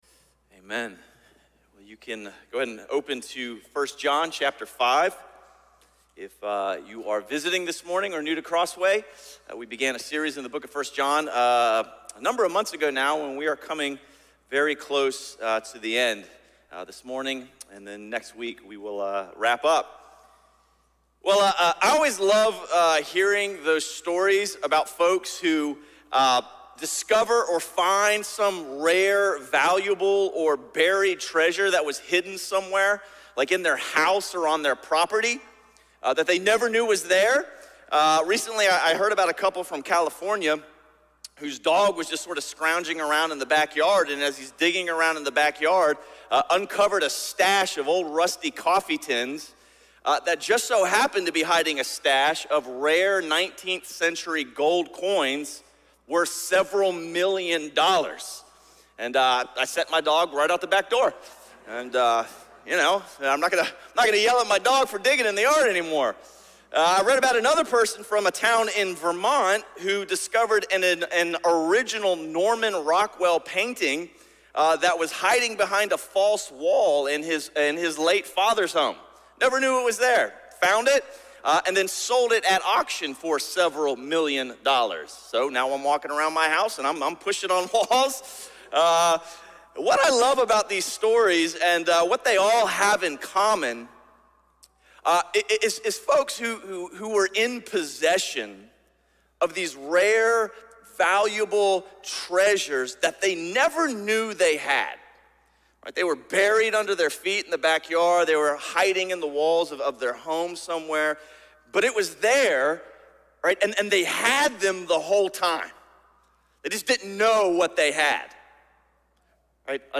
A message from the series "Easter."